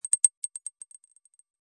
ball-bounce.f36a7c5b3fbc65ff.mp3